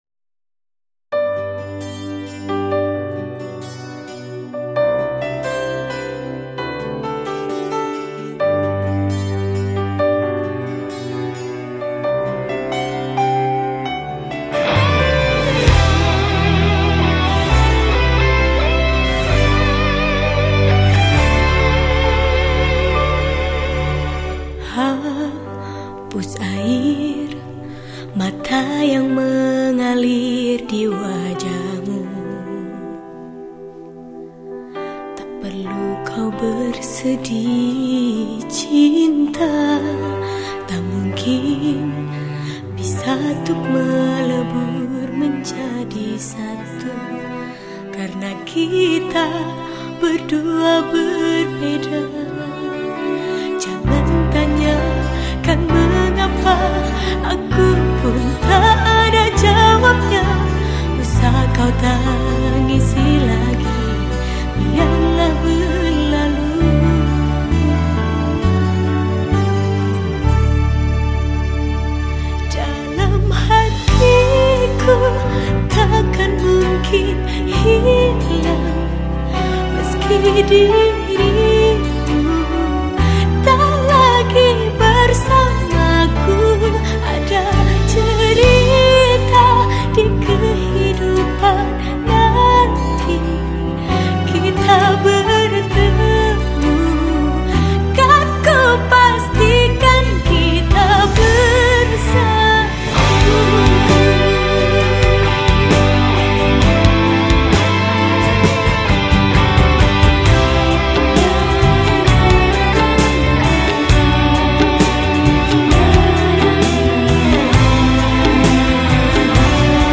Instrumen                                 : Vokal
Genre Musik                            : Dangdut, Pop